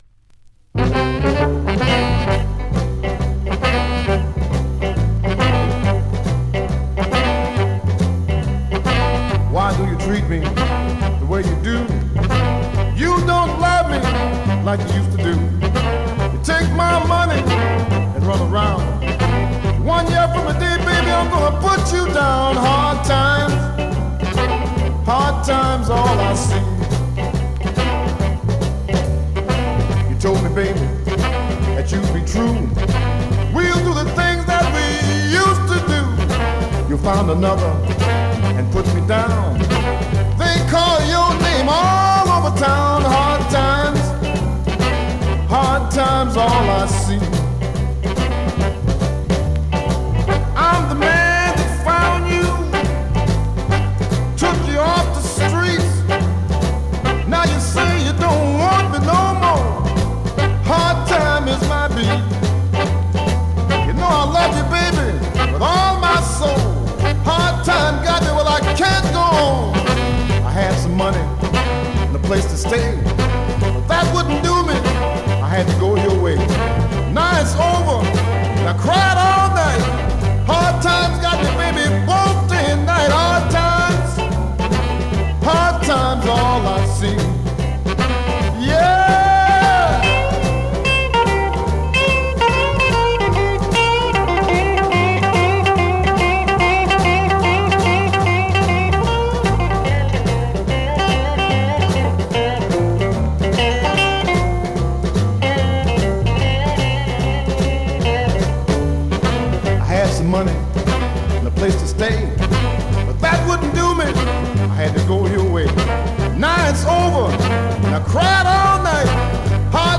Great up-tempo Rnb/ Mod dancer .
R&B, MOD, POPCORN